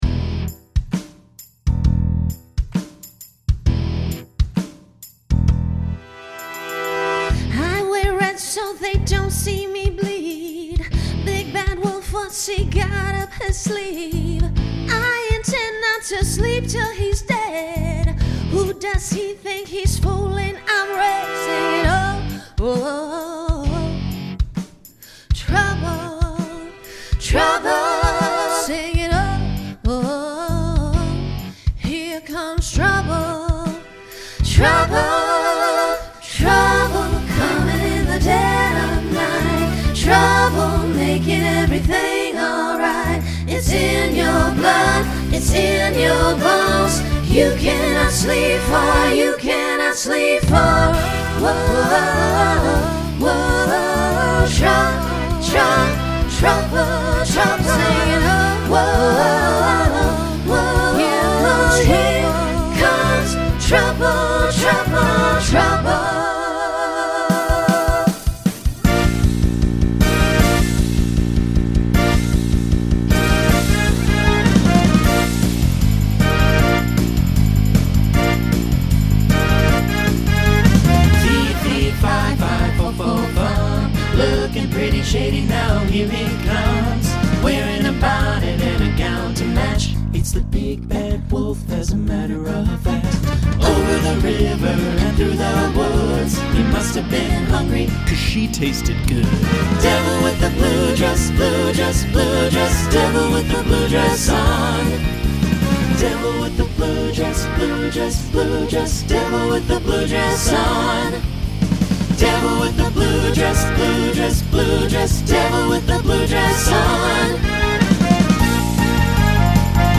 Starts with a small treble group, finishes SATB
Genre Rock
Voicing Mixed